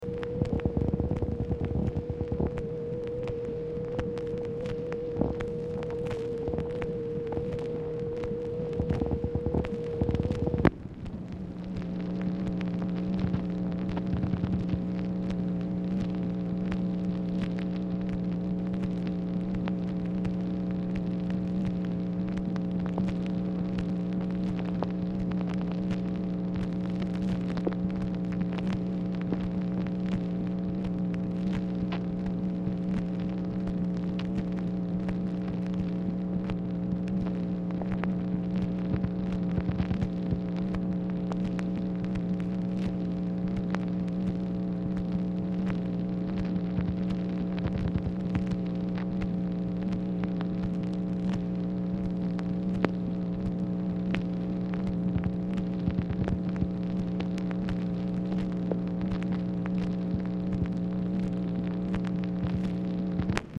Telephone conversation # 8391, sound recording, MACHINE NOISE, 7/26/1965, time unknown | Discover LBJ
Format Dictation belt
Specific Item Type Telephone conversation